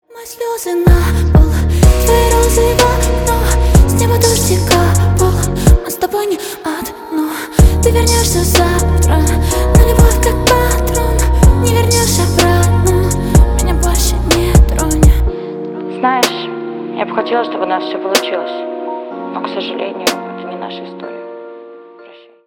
Поп Музыка
грустные # спокойные # тихие